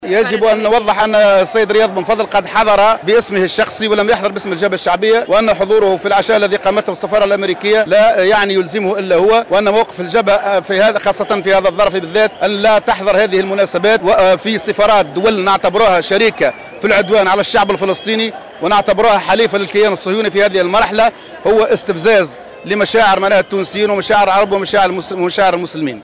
En marge d’une manifestation de soutien au peuple palestinien organisée à Tunis